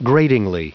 Prononciation du mot gratingly en anglais (fichier audio)
Prononciation du mot : gratingly
gratingly.wav